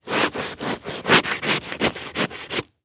Bone Saw Cut Chest Sound
horror